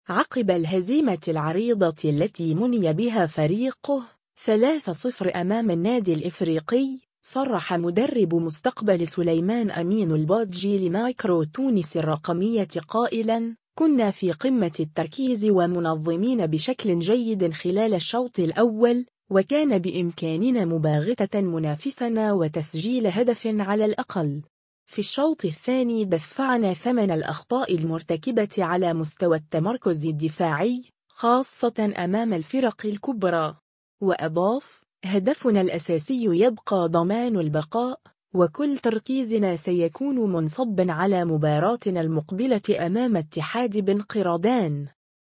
الرابطة المحترفة الأولى: الإفريقي 3-0 مستقبل سليمان، تصريح